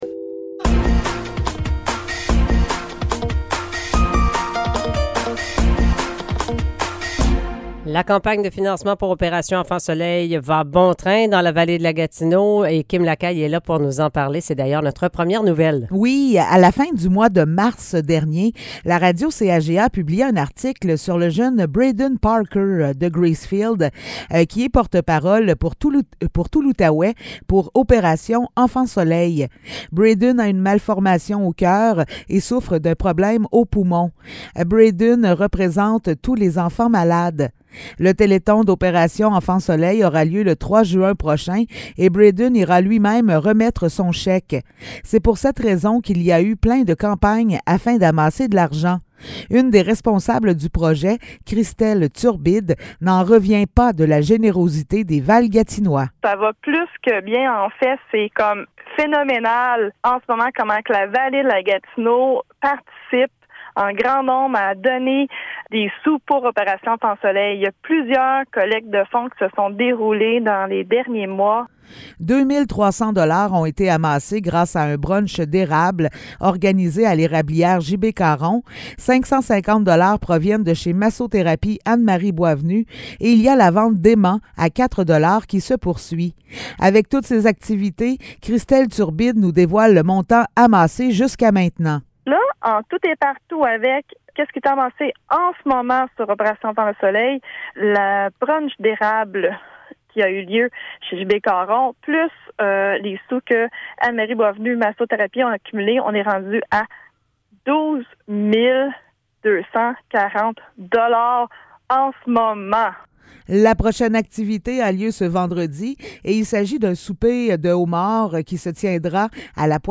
Nouvelles locales - 24 mai 2023 - 8 h